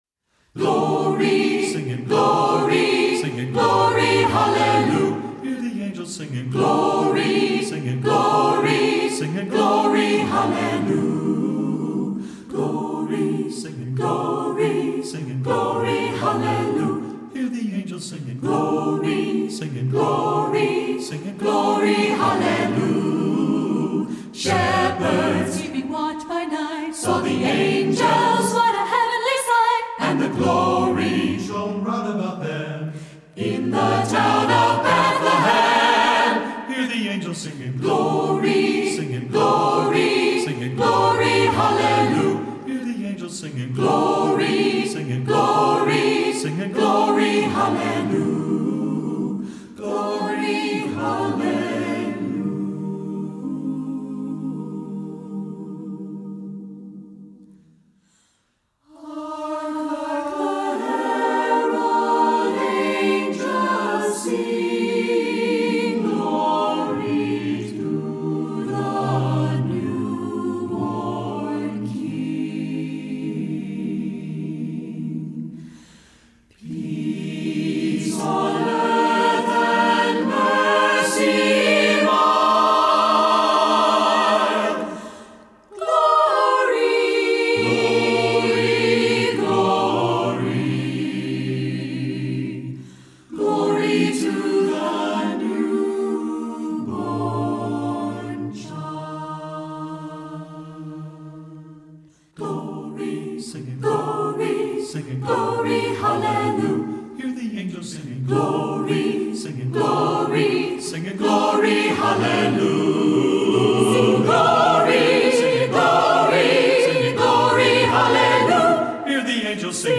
Voicing: SSAB a cappella